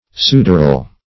sudoral - definition of sudoral - synonyms, pronunciation, spelling from Free Dictionary Search Result for " sudoral" : The Collaborative International Dictionary of English v.0.48: Sudoral \Su"dor*al\, a. [L. sudor.] Of or pertaining to sweat; as, sudoral eruptions.